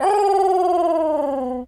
pigeon_call_angry_14.wav